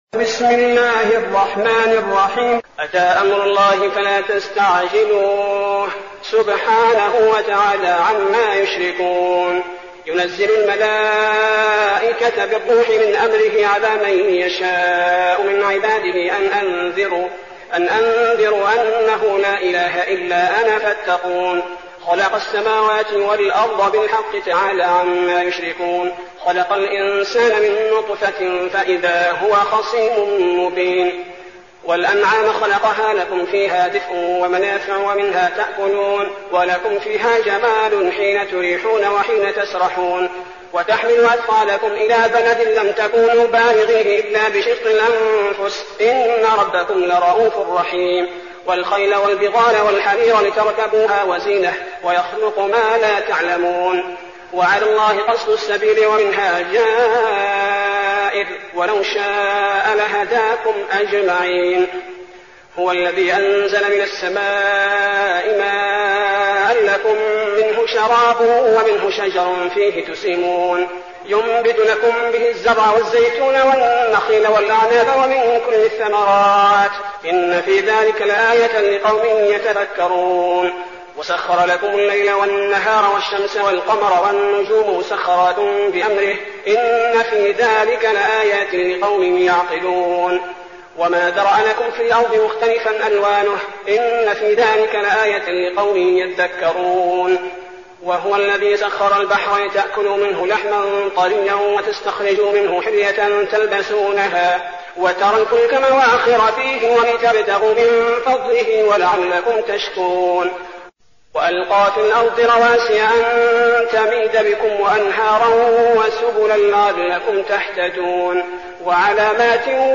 المكان: المسجد النبوي الشيخ: فضيلة الشيخ عبدالباري الثبيتي فضيلة الشيخ عبدالباري الثبيتي النحل